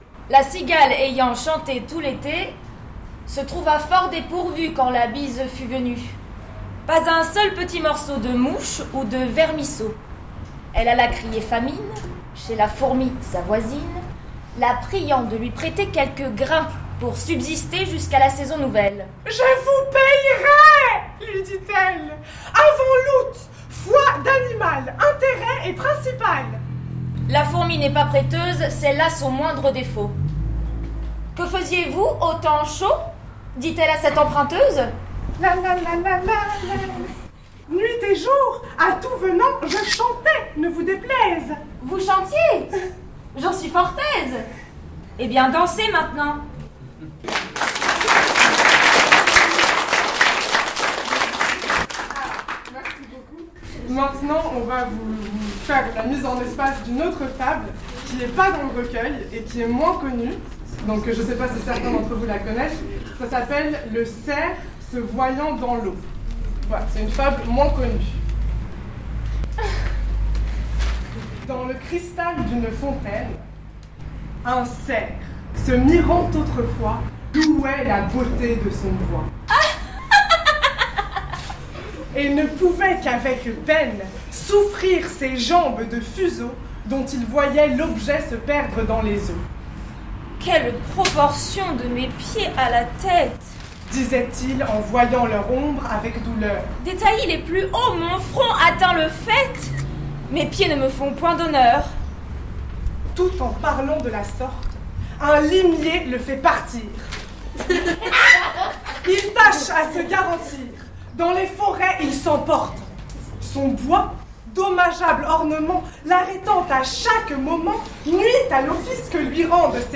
Deux étudiants de l’ERACM ont mis en voix des fables (Photo D.R.)
erac_2_fables.mp3